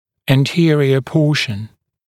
[æn’tɪərɪə ‘pɔːʃn][эн’тиэриэ ‘по:шн]передняя часть (напр. основания черепа)